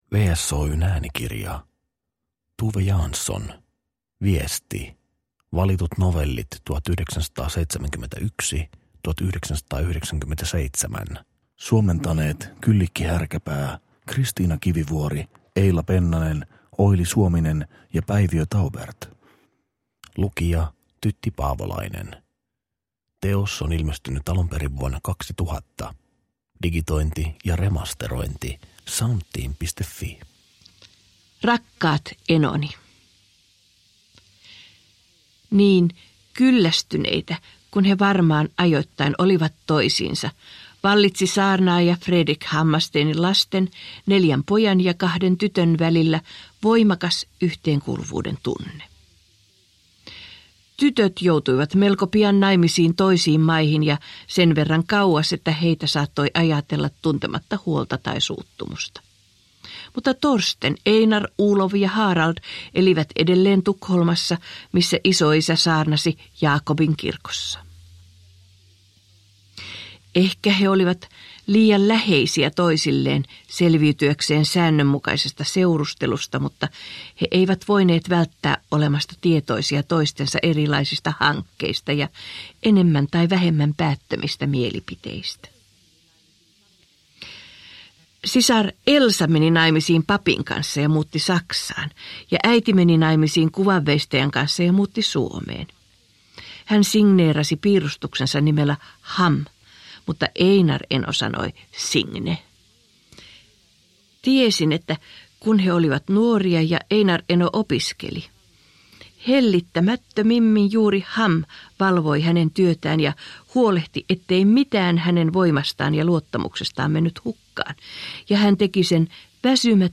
Viesti. Valitut novellit 1971-1997 – Ljudbok – Laddas ner